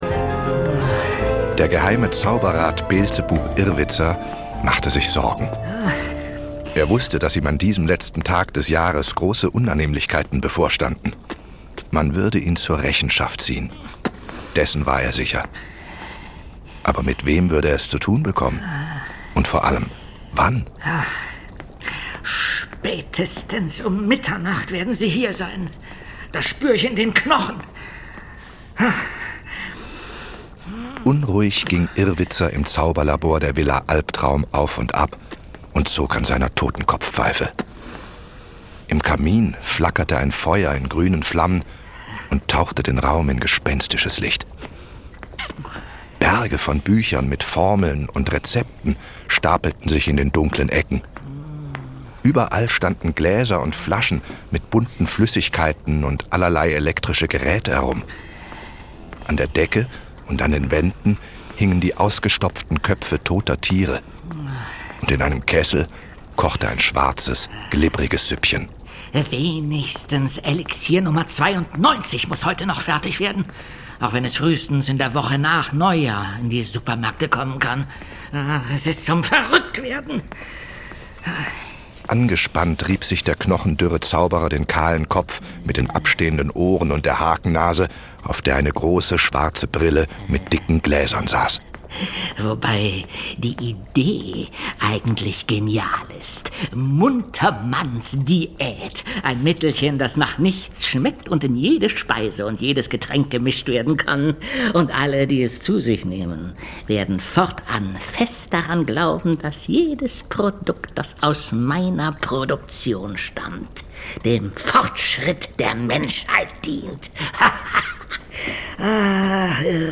Es zischt und blubbert im Wunschpunsch-Labor!
Das Ganze wird sehr genial von den Sprechern in Szene gesetzt und geschickt von passender Musik und passenden Geräuschen untermalt.